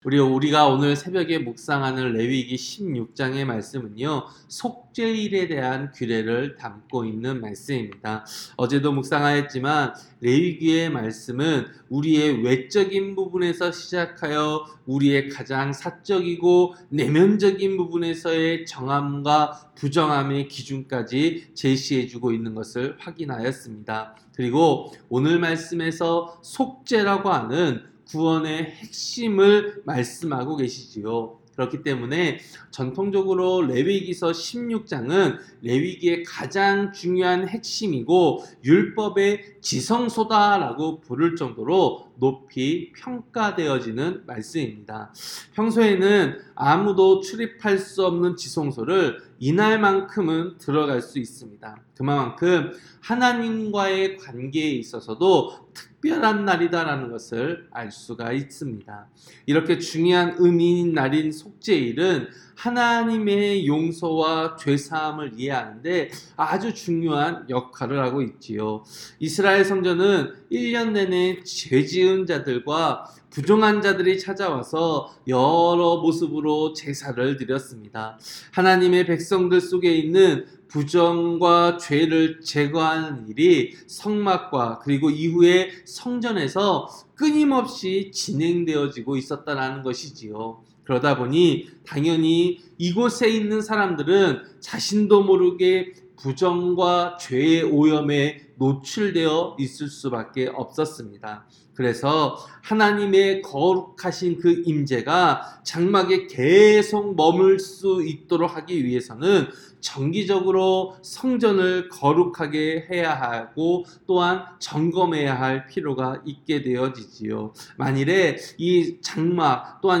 새벽설교-레위기 16장